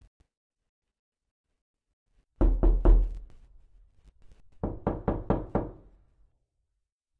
Door Knocking
door-knocking.mp3